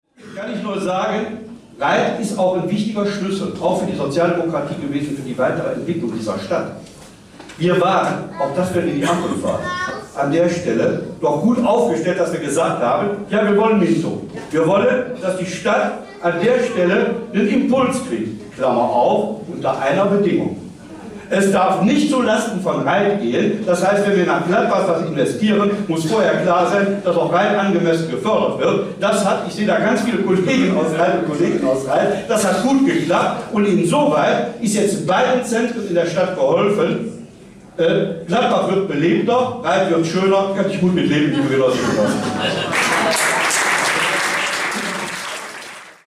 Noch am SPD-Aschermittwoch hatte der SPD-Landtagsabgeordnete Hans-Willi Körfges seinen genossenschaftlichen Zuhörern erklärt, dass die SPD ihre Zustimmung für ein HDZ nur unter der Bedingung gegeben habe, dass dieses „nicht zu Lasten von Rheydt gehen“ dürfe.